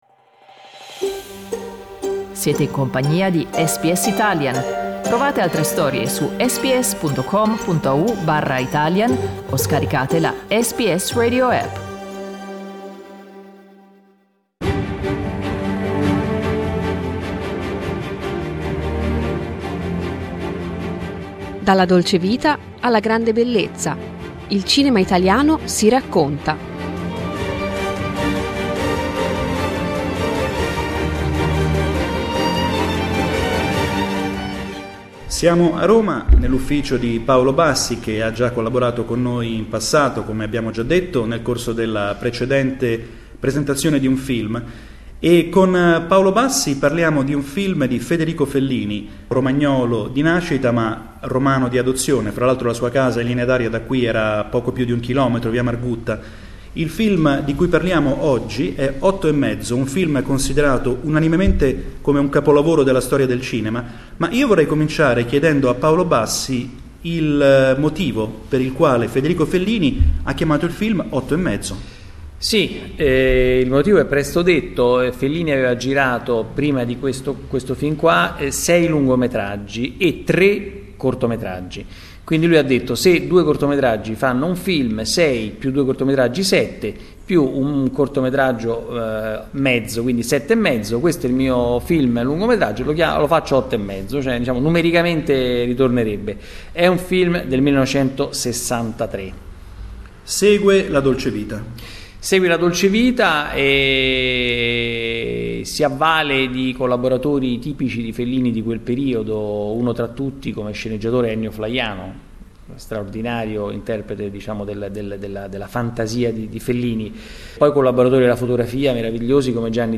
registrata nel 2014 nel suo studio romano.